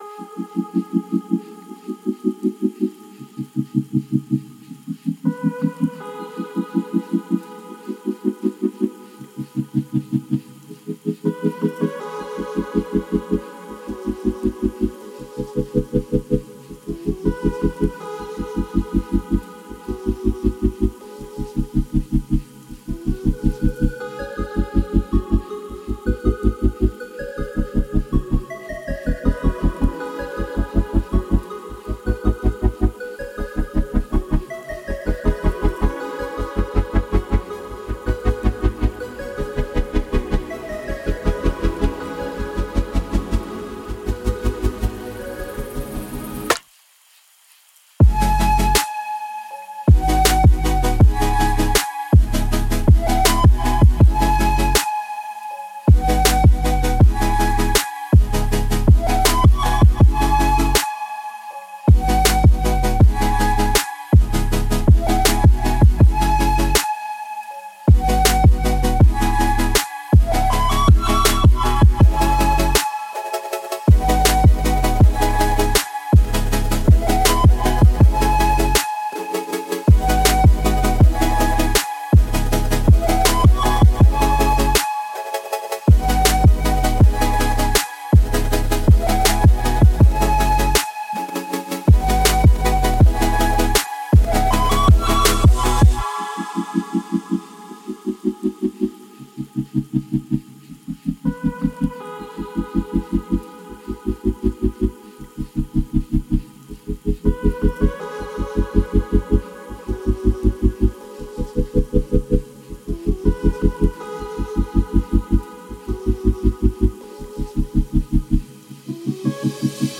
это завораживающий трек в жанре ло-фай хип-хоп